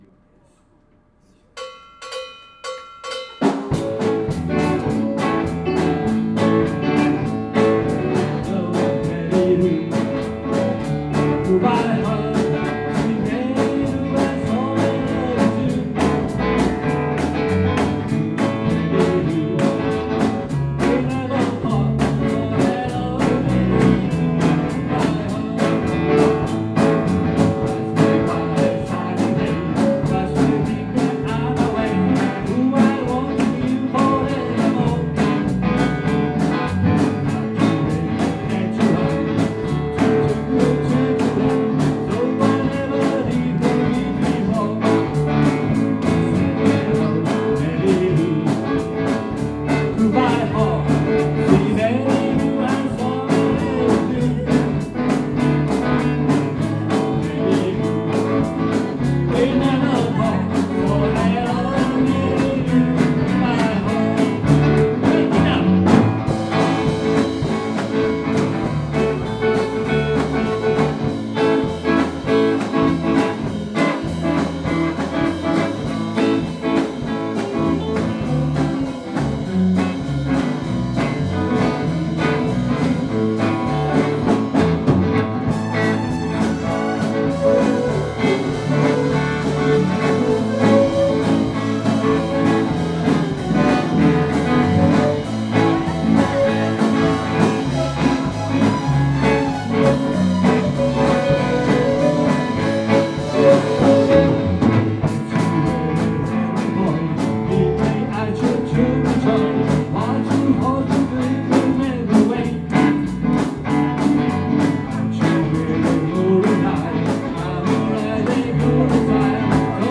2016 Summer Live-2 | The Arou Can